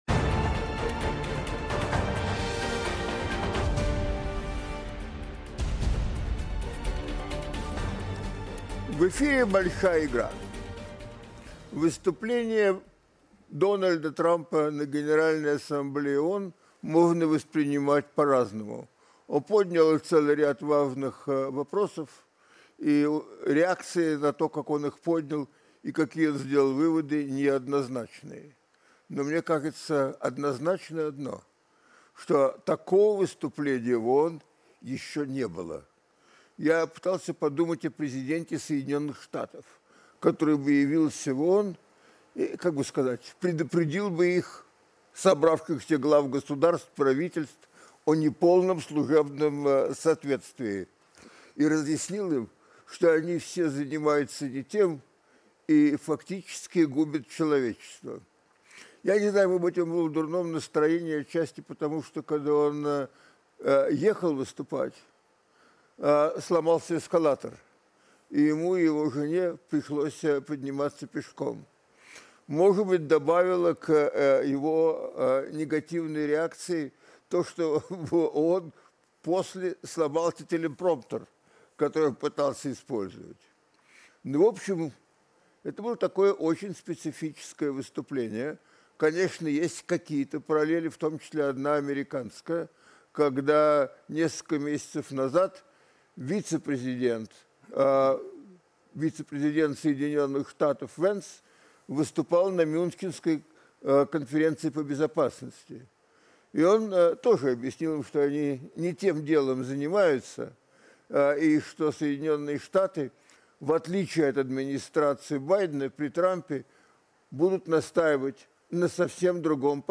В студии подводят итоги дня. Актуальная информация о ходе военной спецоперации России на Украине.